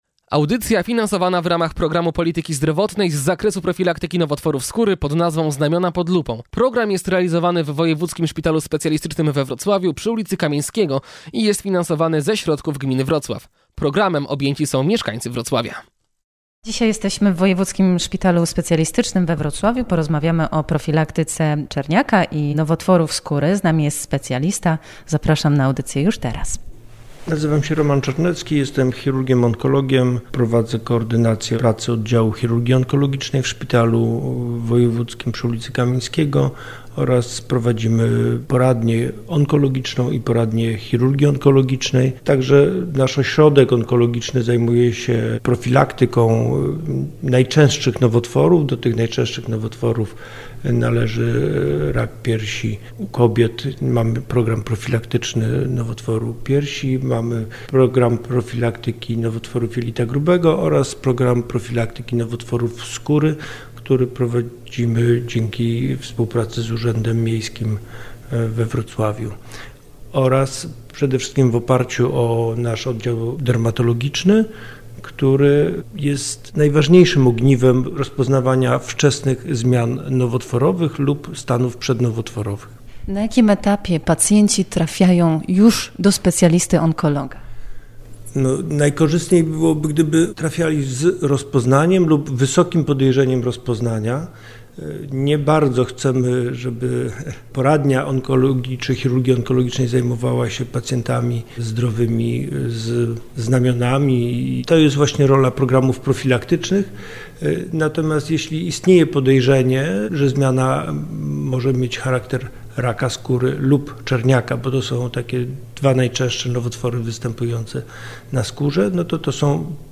Emisja audycji już dziś (czwartek, 21 grudnia) po godz. 11:00.